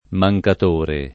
mancatore
mancatore [ ma j kat 1 re ]